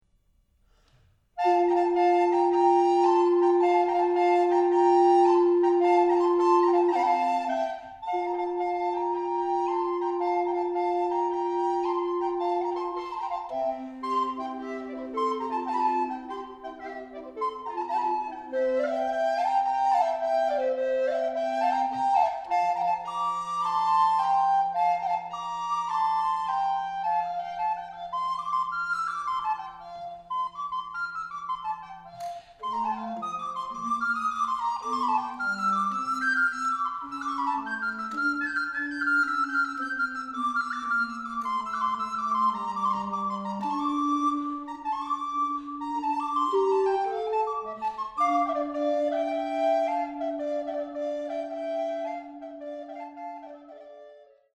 Les extraits proposés en MP3 ont été enregistrés en novembre 2004 dans le but de réaliser un disque de présentation.
Baroque
SA SAT AB